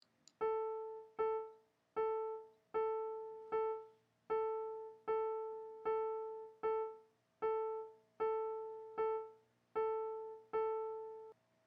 593. 7:4 w differ sound durs
tag:Diff Dur Rat notes.